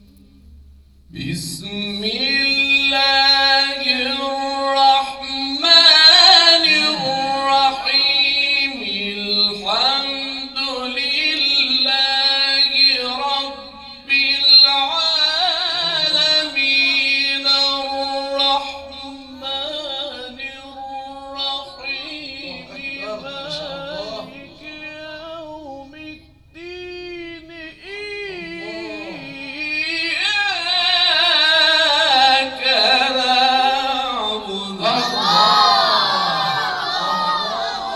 گروه شبکه اجتماعی: فرازهای صوتی از قاریان ممتاز و تعدادی از قاریان بین‌المللی کشورمان را می‌شنوید.
در جلسه مجمع یس